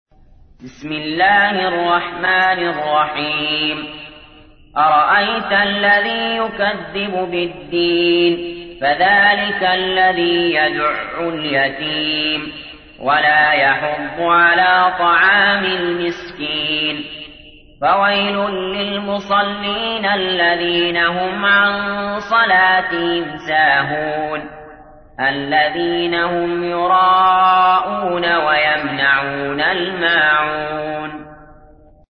تحميل : 107. سورة الماعون / القارئ علي جابر / القرآن الكريم / موقع يا حسين